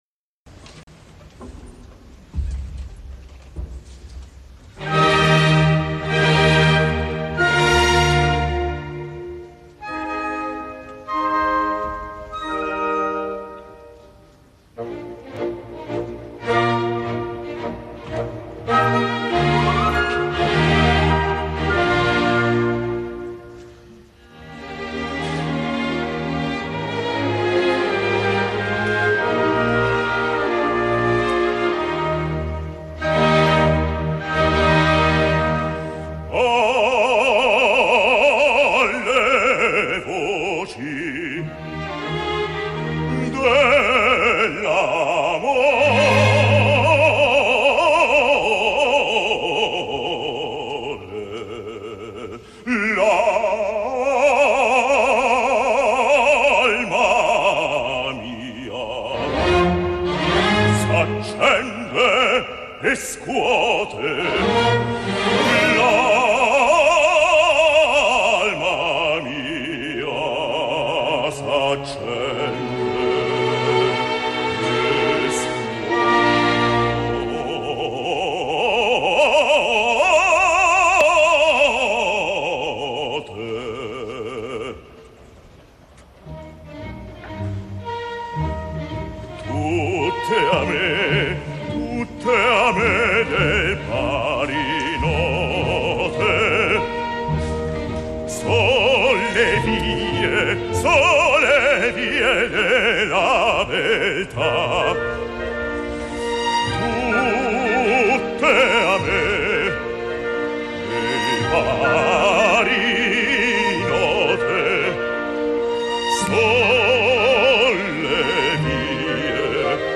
Teatro Rossini
Orchestra Sinfonica G. Rossini